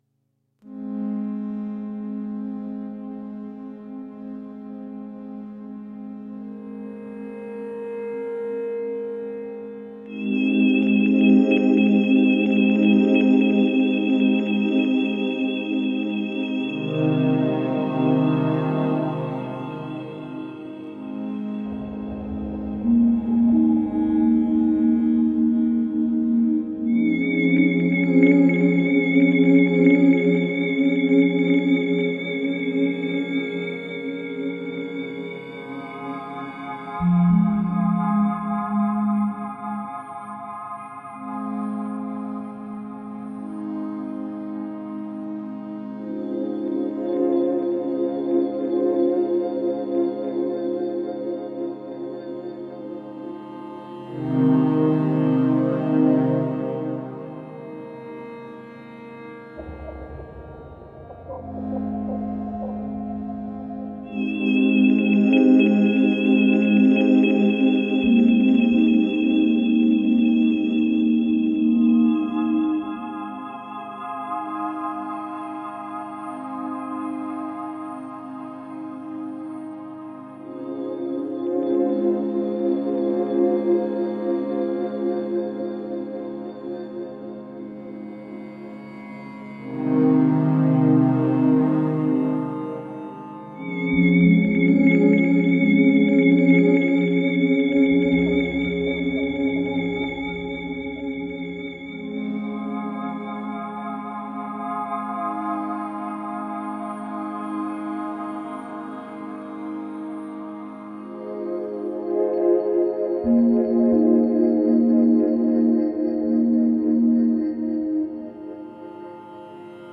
Atmospheric ambient/electronic music.
Tagged as: Ambient, Experimental, Massage, Space Music